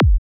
FLC-Kick-Parandroid-F.wav